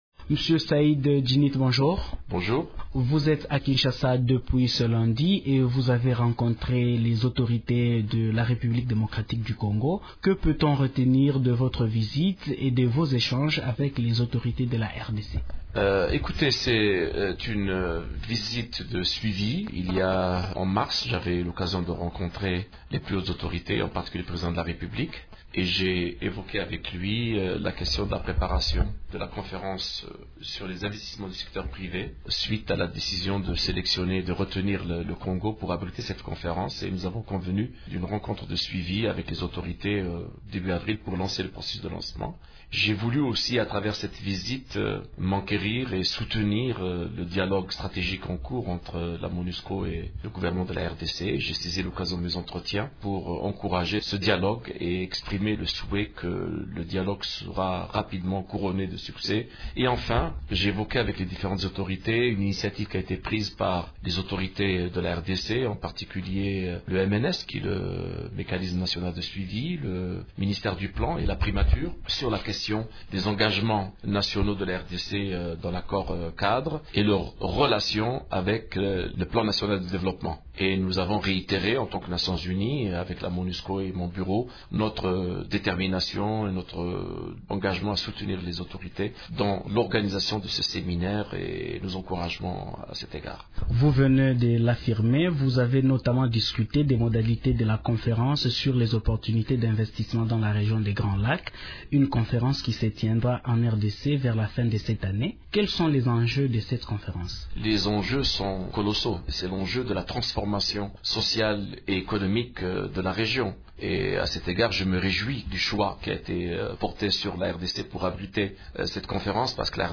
L’envoyé spécial du secrétaire général de l’Onu dans les Grands Lacs plaide pour le rétablissement rapide de la coopération militaire entre la Monusco et le gouvernement congolais. Invité de Radio Okapi ce matin, Saïd Djinnit a indiqué que la présence de la mission onusienne est importante dans le processus de stabilisation de la RDC.